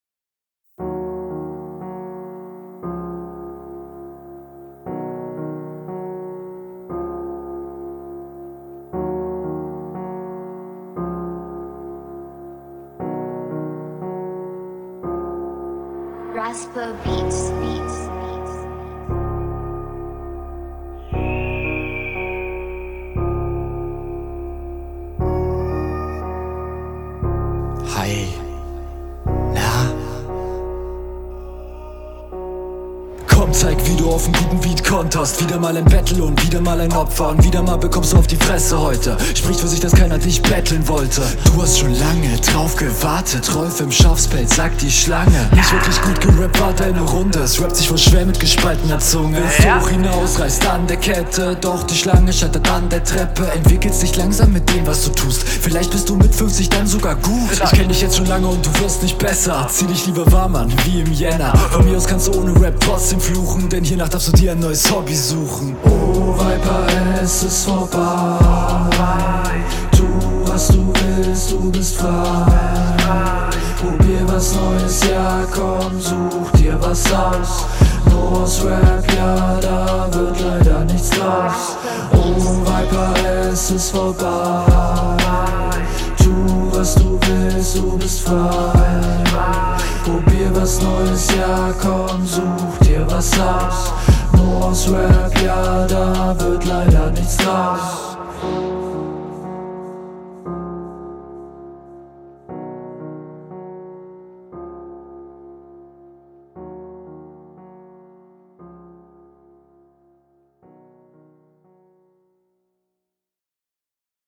Das Beatintro ist so ungefähr mindestens 20 Sekunden zu lang.